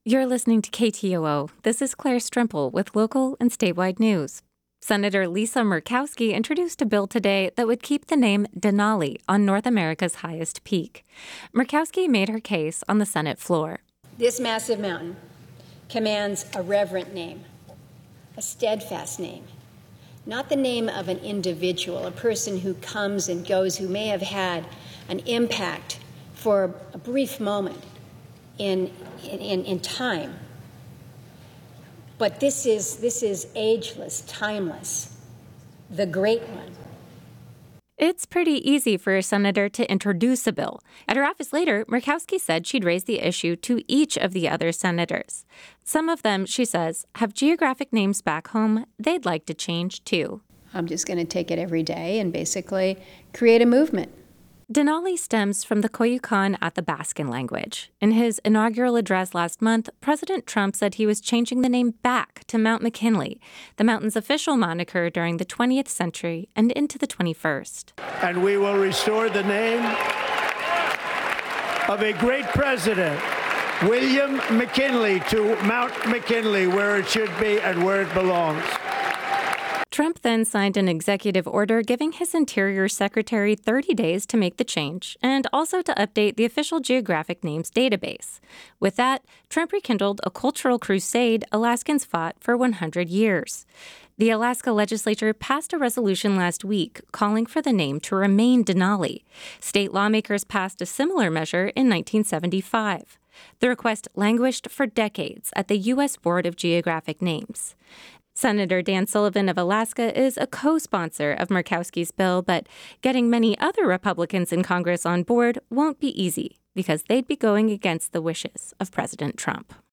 Newscast – Friday, Feb. 14, 2025 - Areyoupop